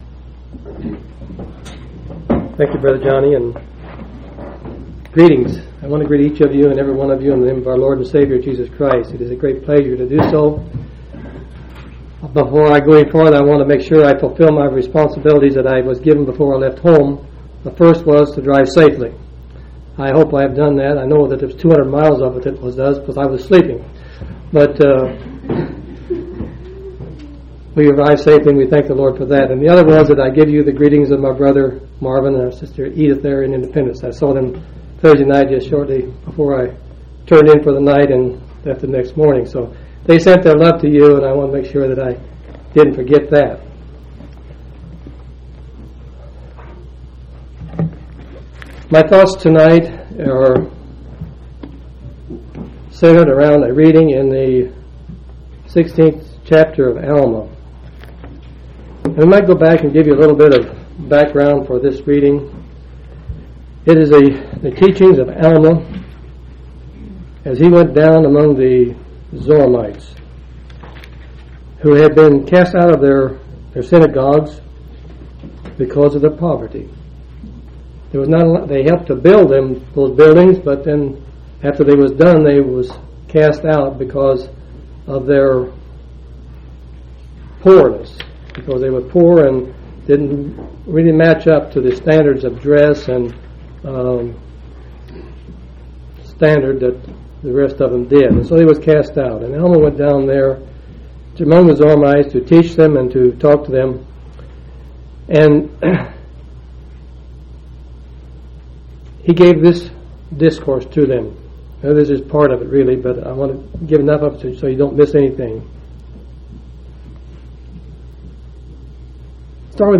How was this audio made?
6/13/1998 Location: Colorado Reunion Event